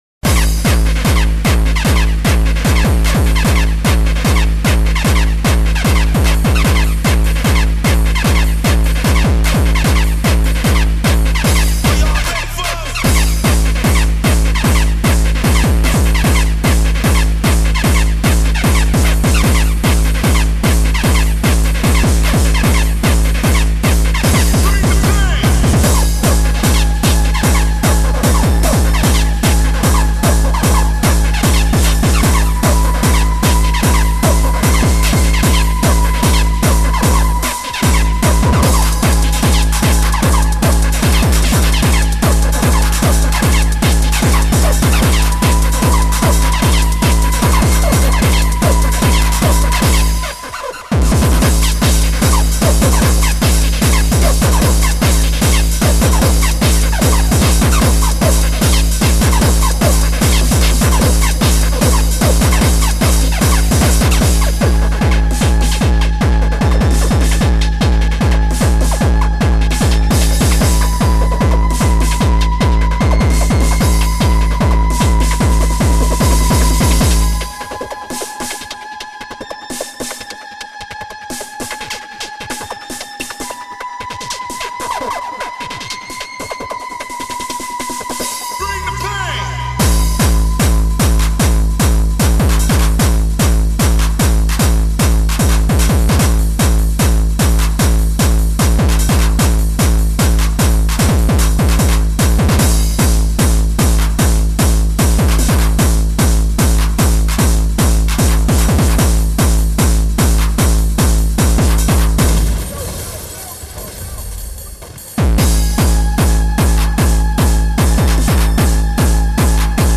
хардкор